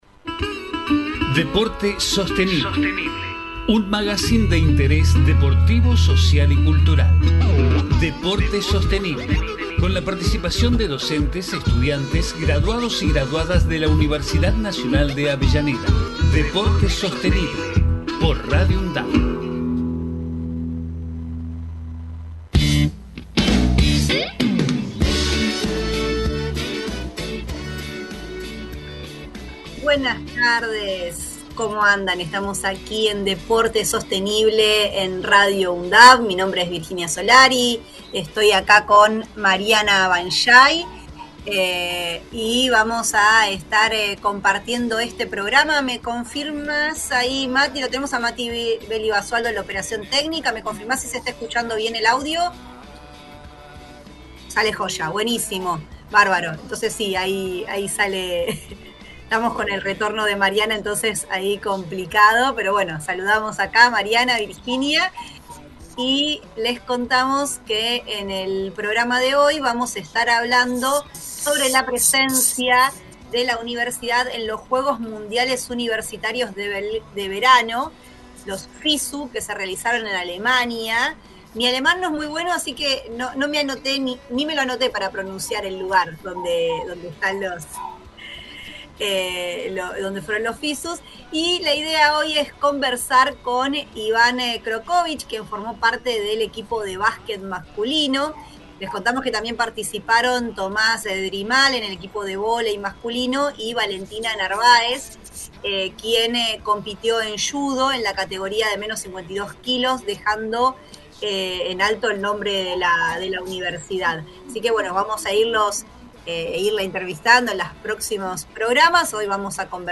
Deporte Sostenible Texto de la nota: En cada programa se busca abordar la vinculación estratégica entre gestión deportiva, desarrollo sostenible, salud, cultura, medio ambiente e inclusión social, realizando entrevistas, columnas especiales, investigaciones e intercambio de saberes. Magazine de interés deportivo, social y cultural que se emite desde septiembre de 2012.
Con la participación de docentes, estudiantes y graduados/as de la Universidad Nacional de Avellaneda.